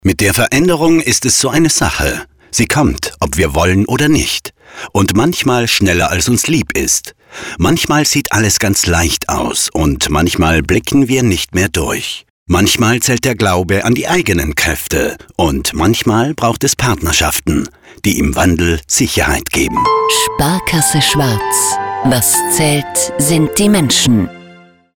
werbekampagne-2018-radiospot-sparkasse-schwaz.mp3